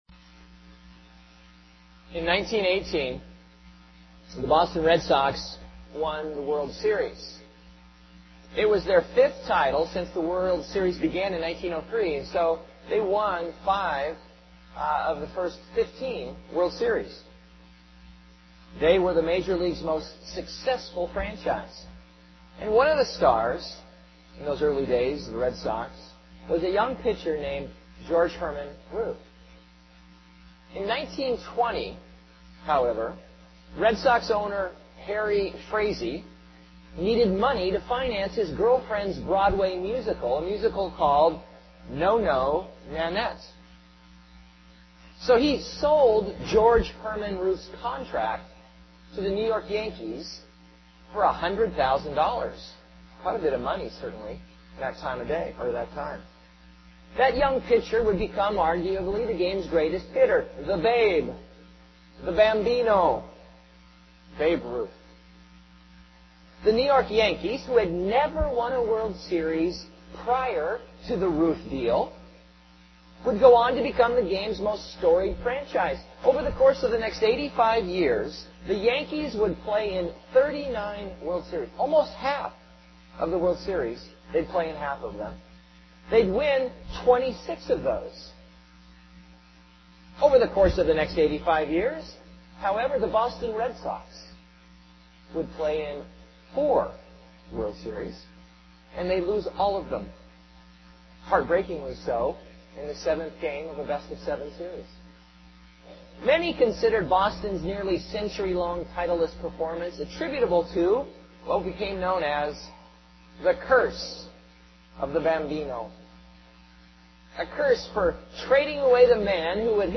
C. Lessons of Revelation [unfortunately, we faced some technical problems recording this sermon so it is not the usual high quality] Just His Return Left October 2, 2011 I. Introduction II.